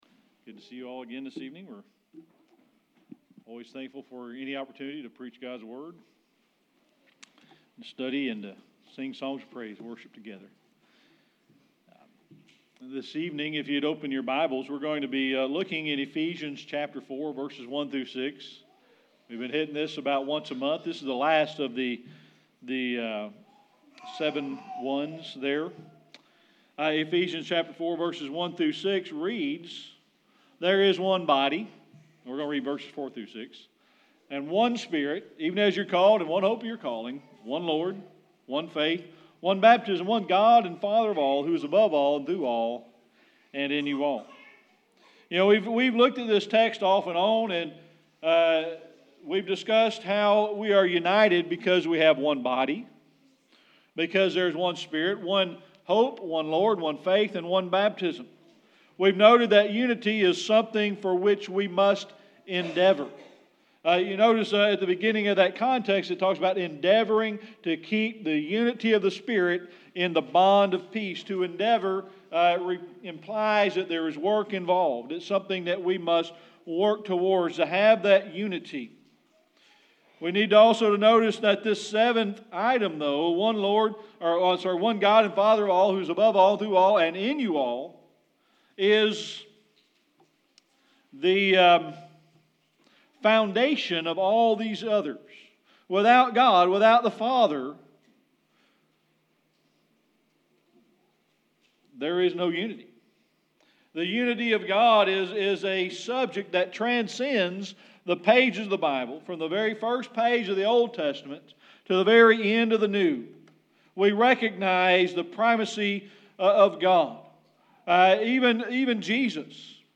Service Type: Sunday Evening Worship